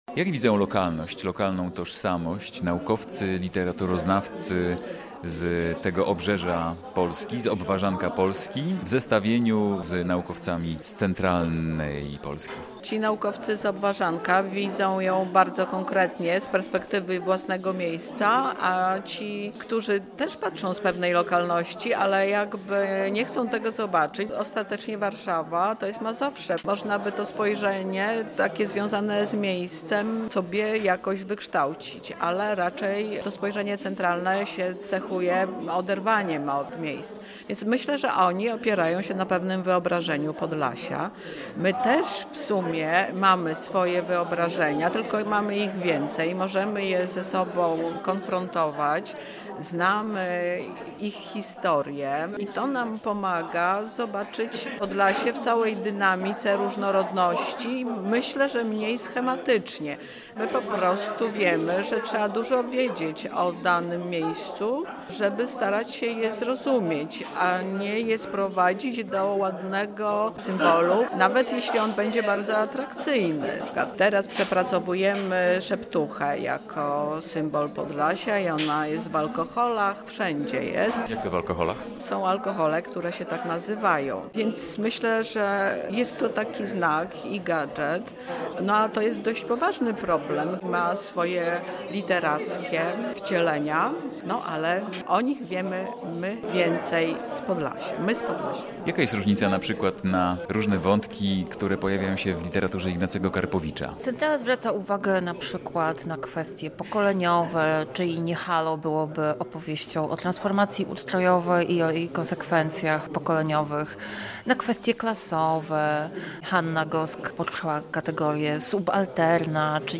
Teraz seria pytań przy okazji dyskusji w Centrum im. Zamenhofa na temat Podlaskiego regionalizmu literackiego. Czym różni się spojrzenie badaczy literatury z centralnej Polski na Podlasie od spojrzenia lokalnych naukowców?